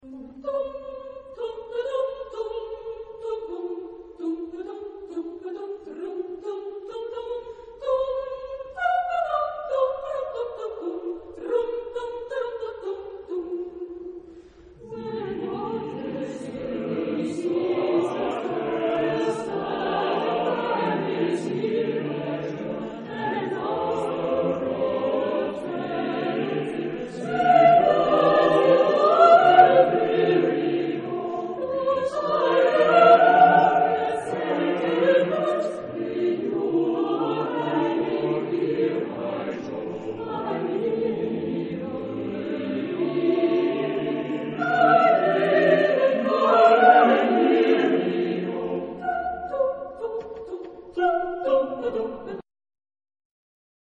Genre-Style-Forme : contemporain ; Profane ; Populaire
Caractère de la pièce : martial ; fantasque ; léger ; modéré
Solistes : Baryton (1) OU Soprano (1)  (2 soliste(s))
Tonalité : si bémol majeur